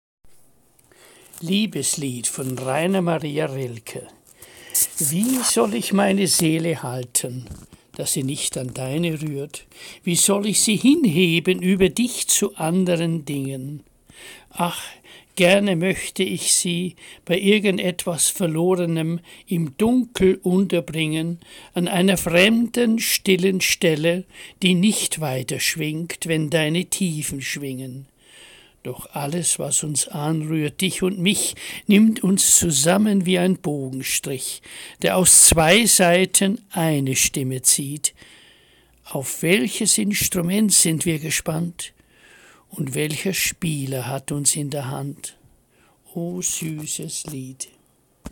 Lesung - Musikvideo